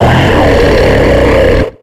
Cri de Kaimorse dans Pokémon X et Y.